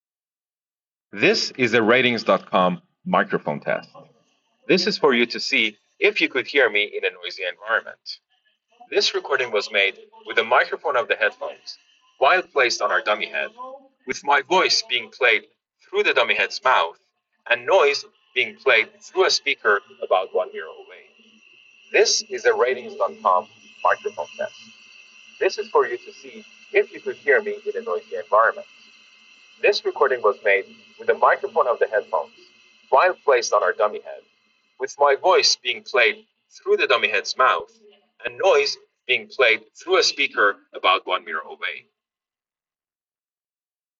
loud environments.